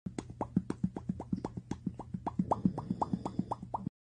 cat-mouth-noise-192-kbps_FWZc3Sl.mp3